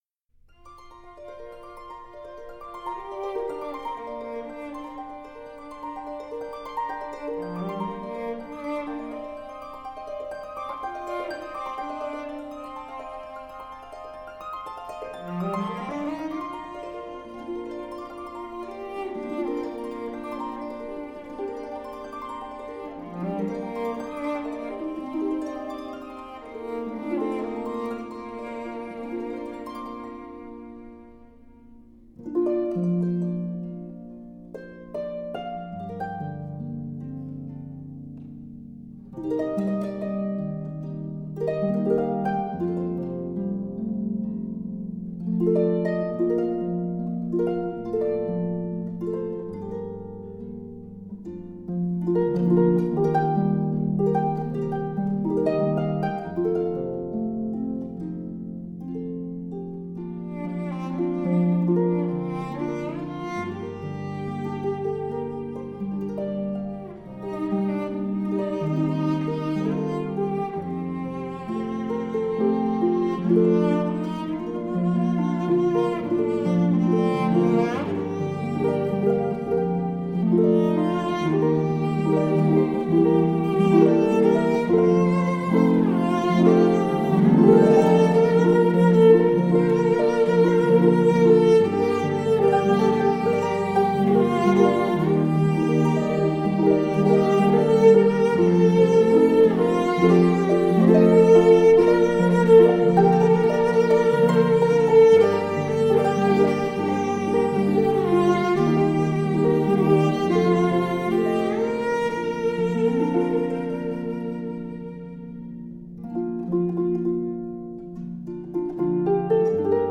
室内乐也摇摆
13首唤醒青春记忆的校园民歌　最具质感的原音重现